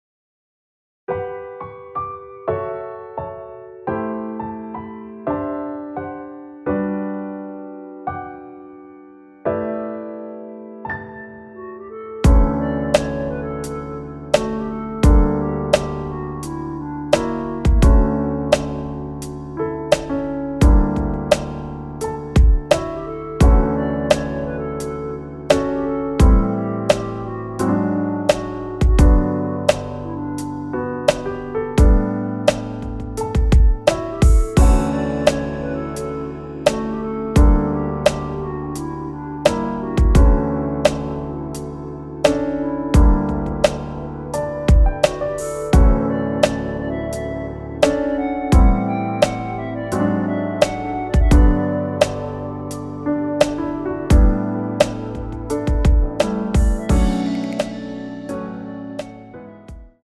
원키에서(-1)내린 멜로디 포함된 MR입니다.(미리듣기 확인)
◈ 곡명 옆 (-1)은 반음 내림, (+1)은 반음 올림 입니다.
앞부분30초, 뒷부분30초씩 편집해서 올려 드리고 있습니다.